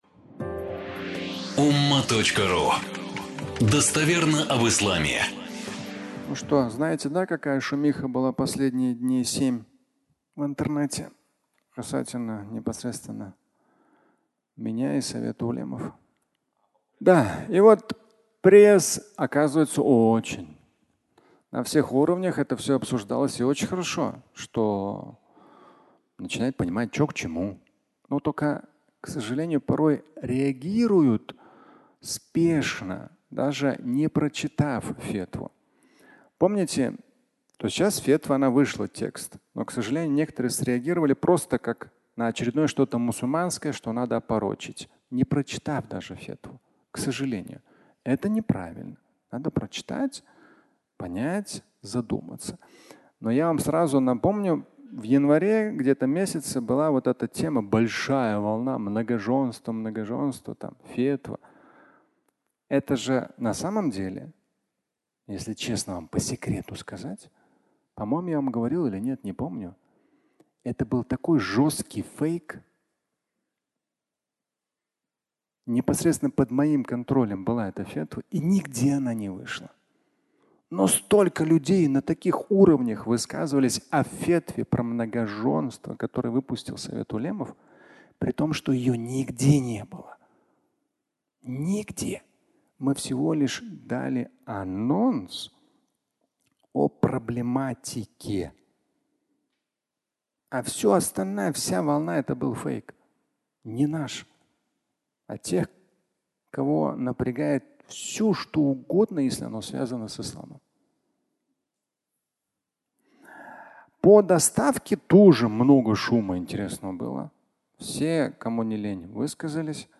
Логистика и доставка (аудиолекция)